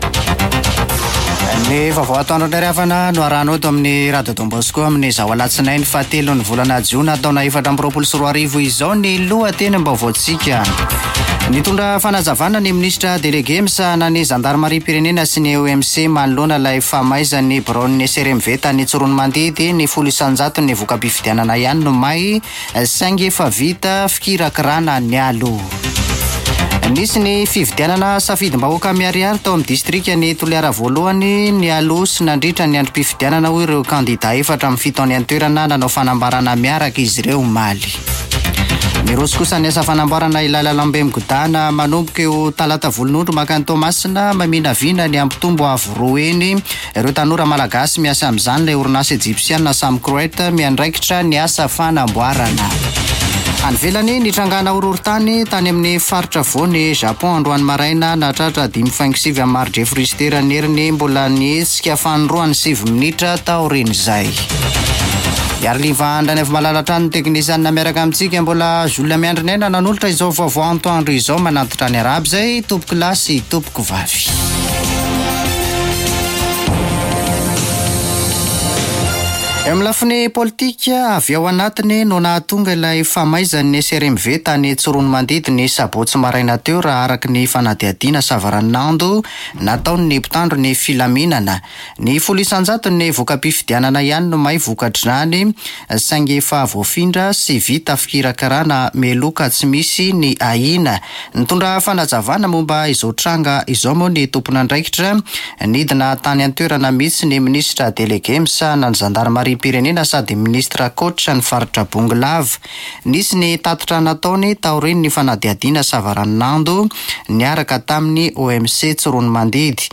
[Vaovao antoandro] Alatsinainy 03 jona 2024